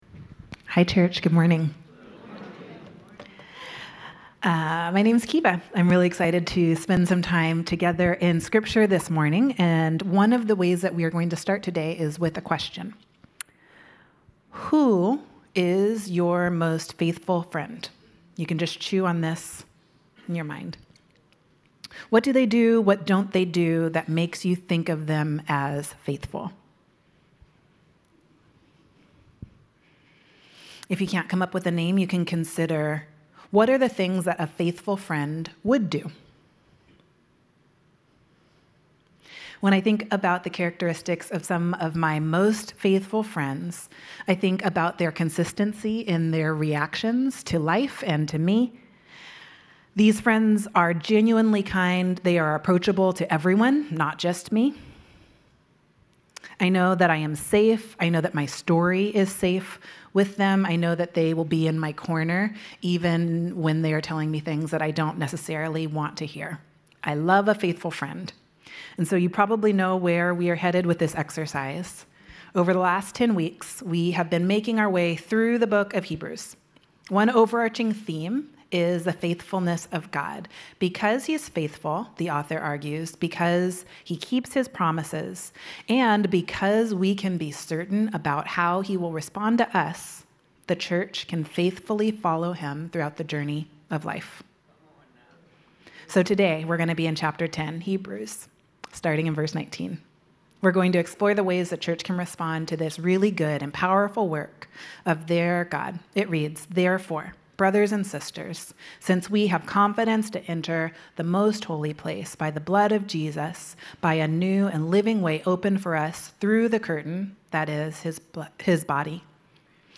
this morning's message